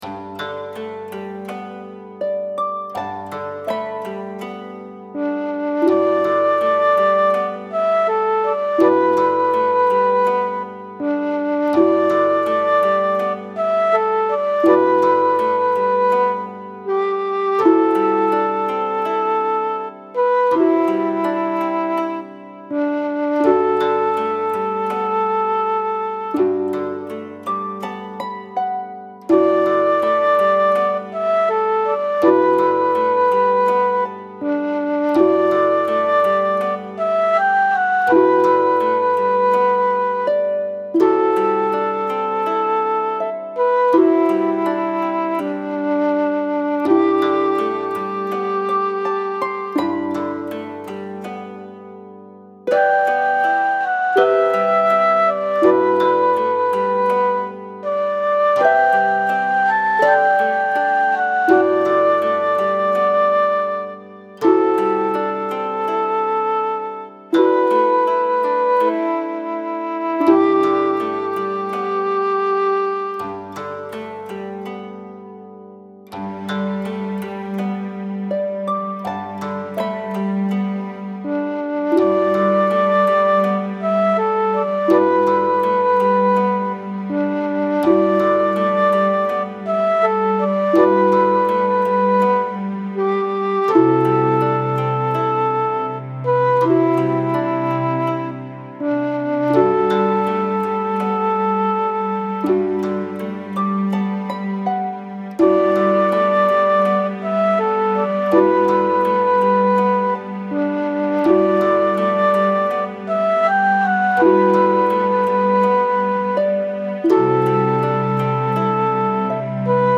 和風で、ほのぼのした雰囲気のインストです。
ループします。